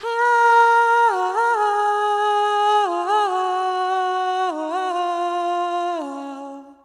描述：舞蹈声乐循环
Tag: 140 bpm Dance Loops Vocal Loops 1.15 MB wav Key : Unknown